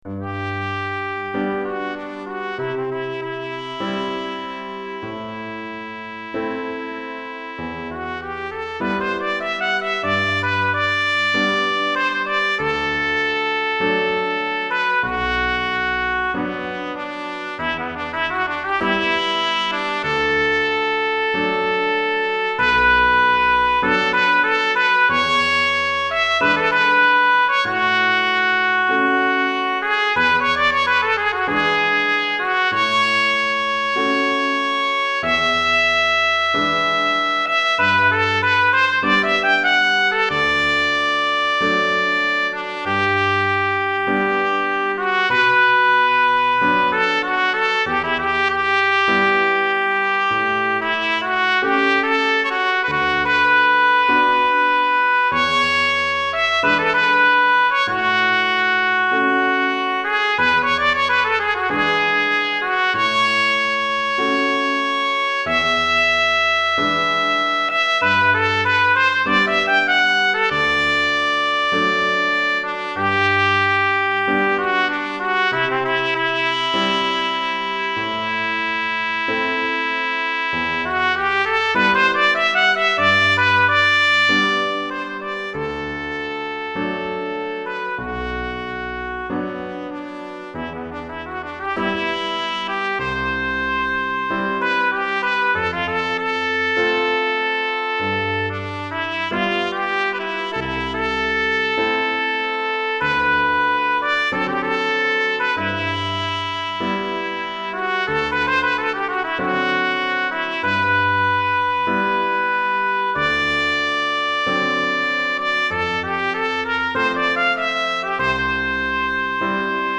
Trompette et Piano